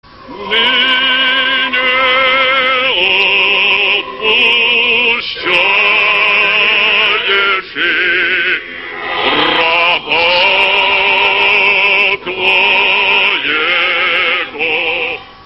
Духовная музыка (900)
Правильный ответ: Фёдор Иванович Шаляпин